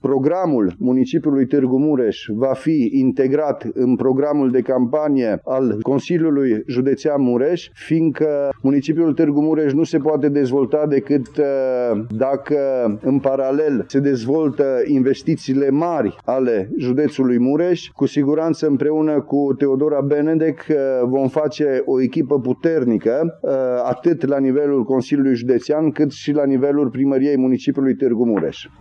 Este declarația candidatului PNL la președinția Consiliului Județean Mureș, Cristian Chirteș.